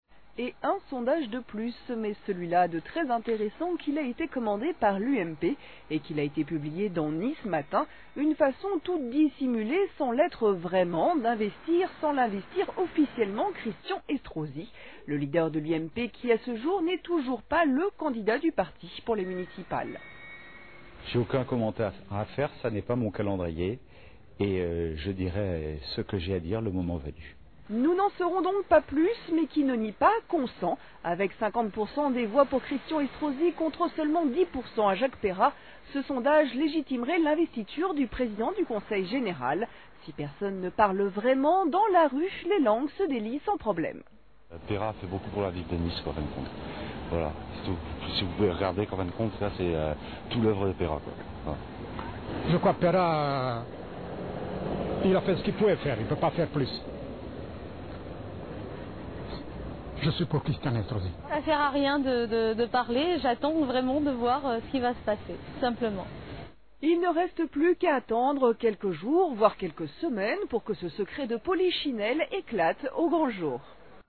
Un extrait du JT de France 3 sur le sondage commandité par l'UMP :